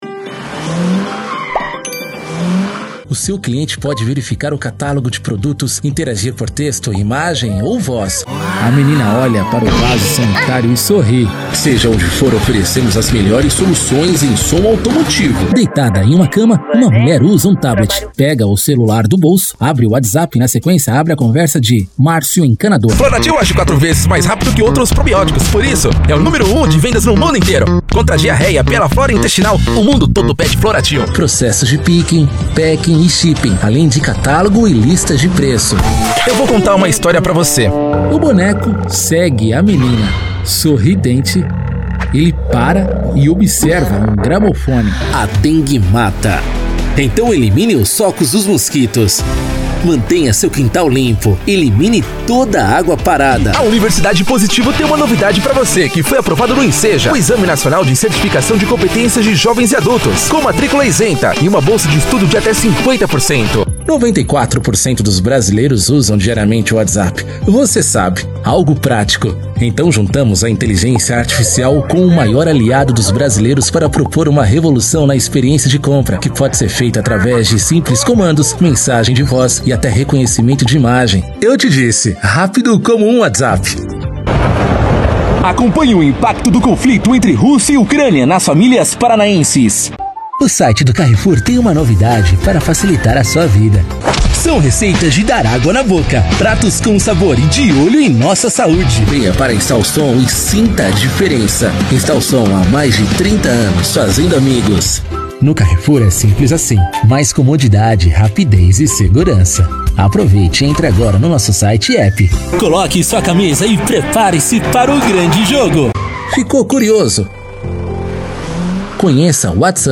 Impacto
Animada